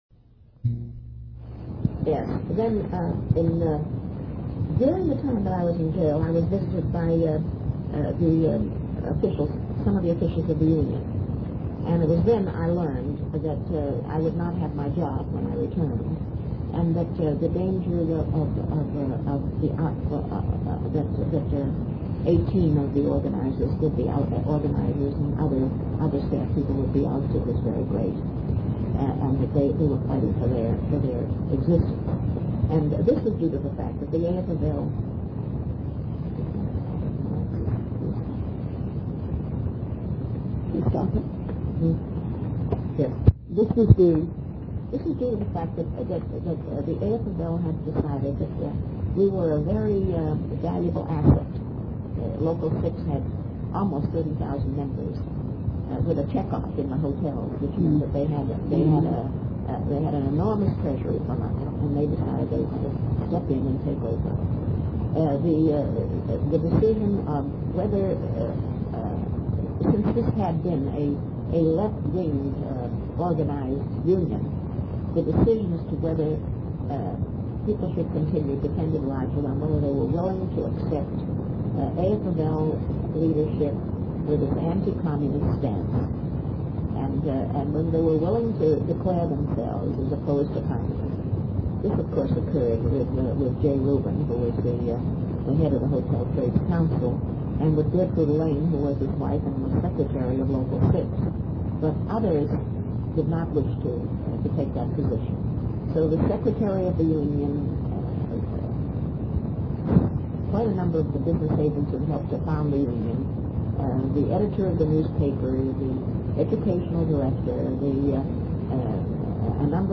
INTERVIEW DESCRIPTION